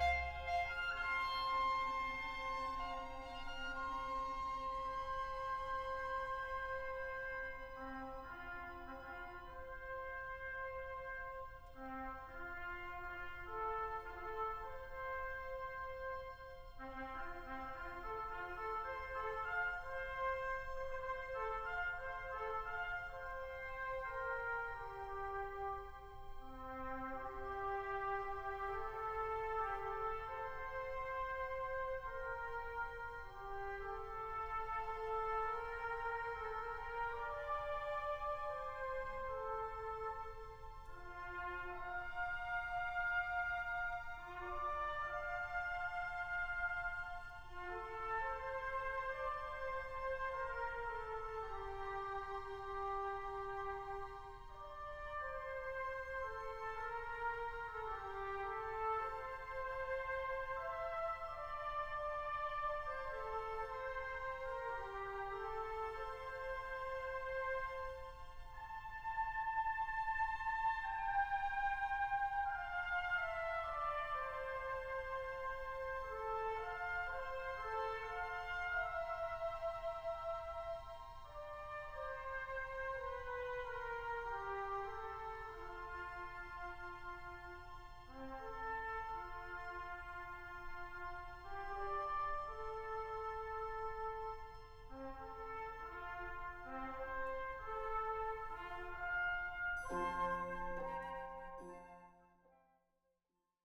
Mahler: 3. Sinfonie (3. Satz, Posthorn-Episode, Ziffer 14)